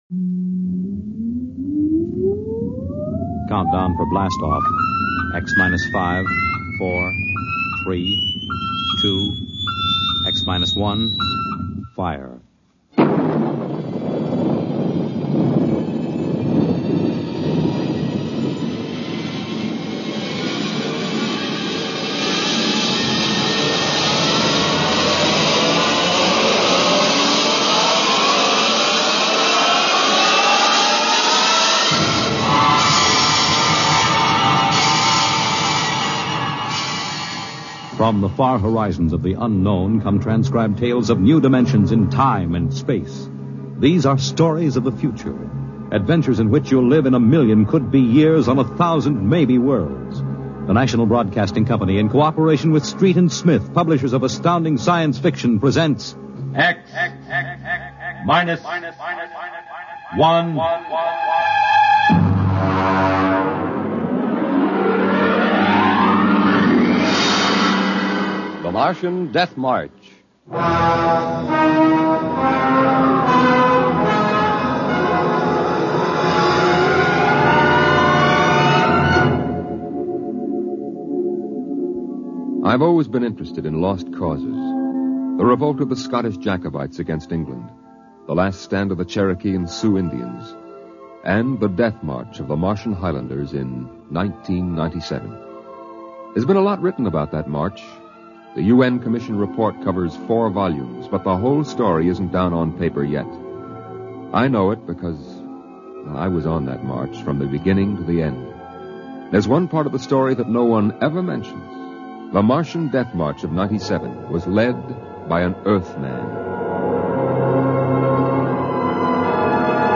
X Minus One was an early radio show that focused on Science Fiction stories.